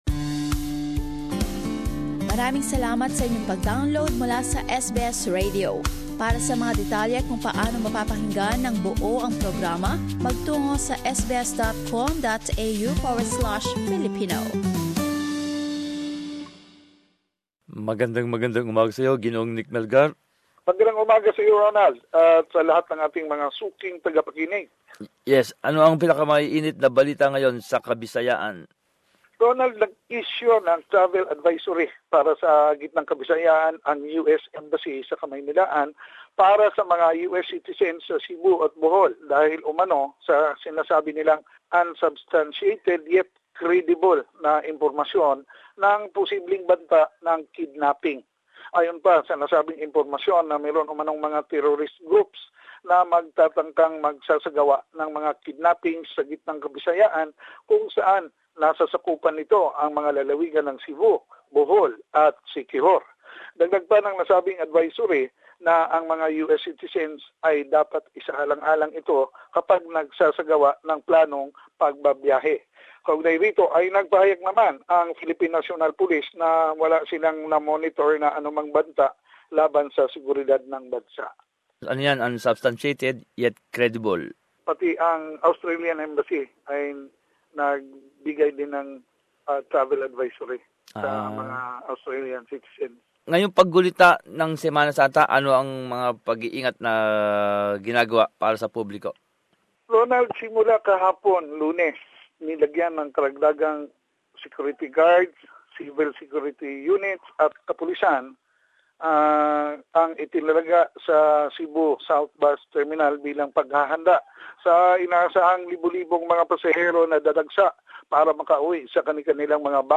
Balitang Bisayas. Buod ng mga pinakahuling ulat buhat sa rehiyon